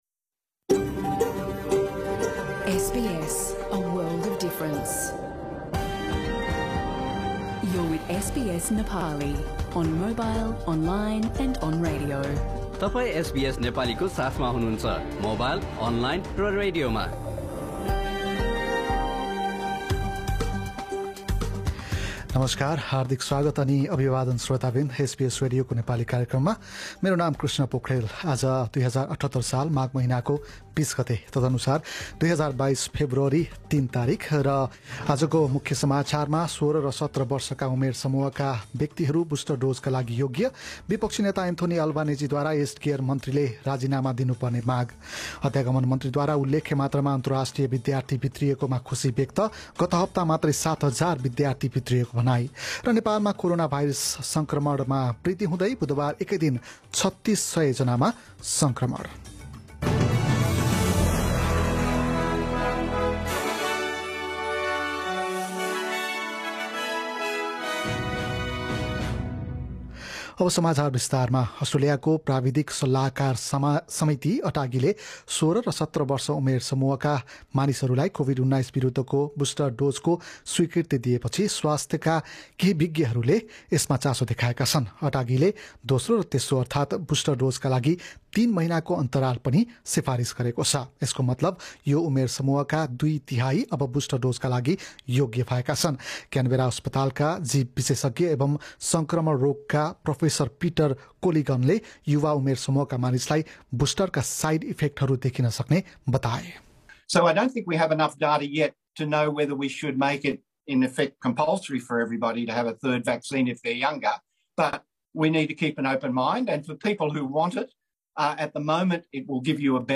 In this bulletin: Labor politicians condemn the Federal government's vaccine booster rollout in the aged care sector, teenagers aged 16 and 17 given permission for booster shots and New Zealand borders to reopen for visitors in a five-stage plan.